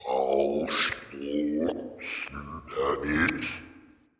demon-voice.mp3